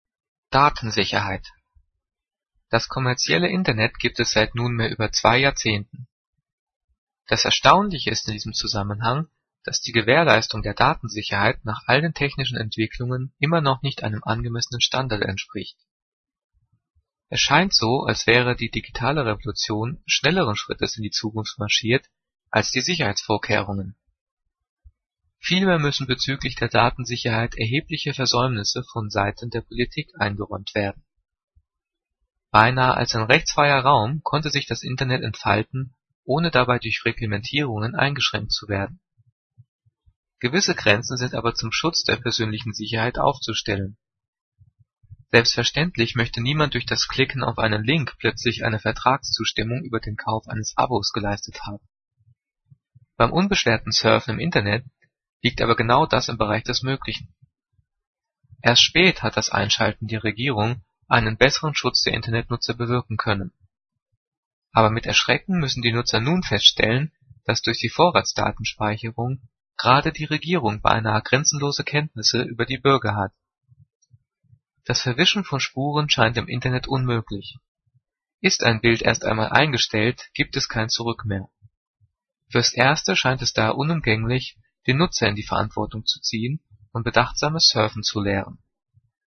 Gelesen:
gelesen-datensicherheit.mp3